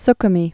(skə-mē, s-)